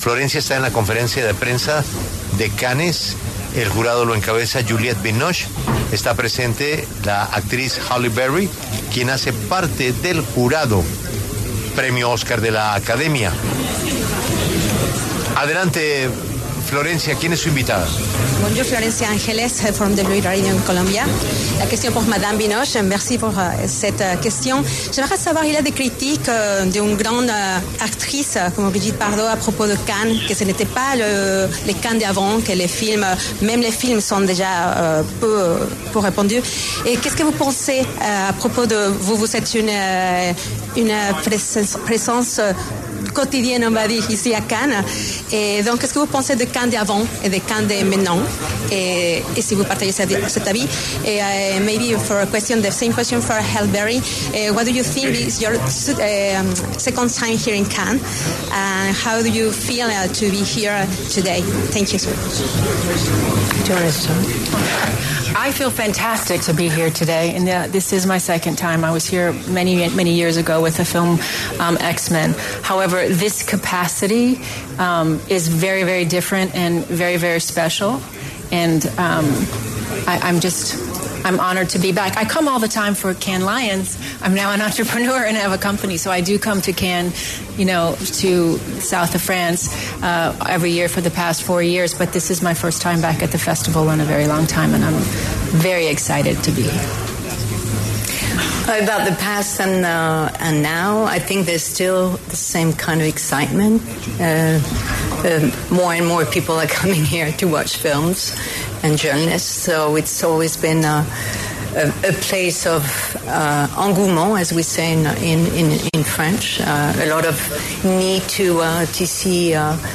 La W estuvo en la rueda de prensa de las reconocidas actrices Halle Berry y Juliette Binoche, quienes hacen parte del jurado en el Festival de Cannes 2025.